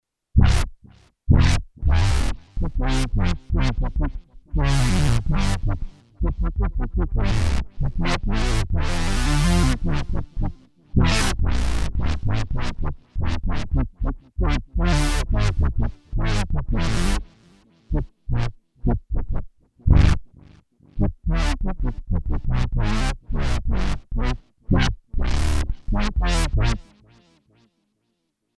NPC Speech Sounds
Each NPC will have their own unique sounding voice pre-generated using synthesizers.
NOTE: These examples use heavy delay/echo which will not feature on the actual speech sounds.